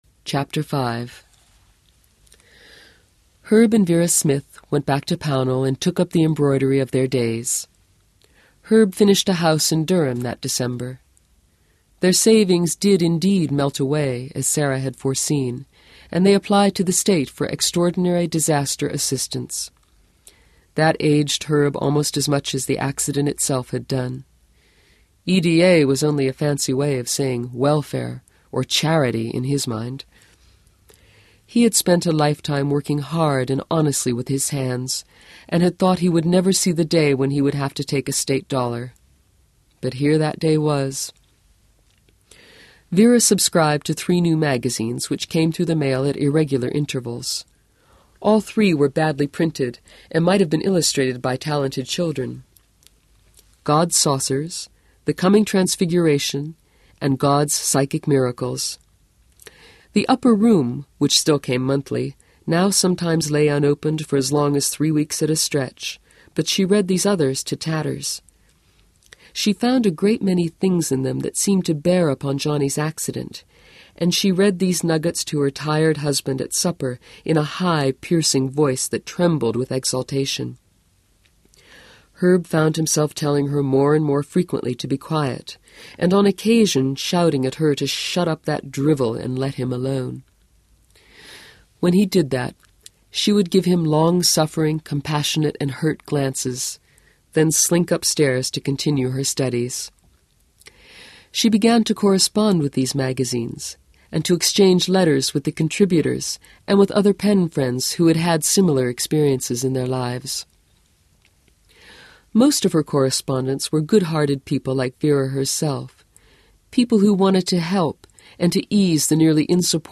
37serv Ebooks/Stephen King/1979 - The Dead Zone (read by Lorelei King)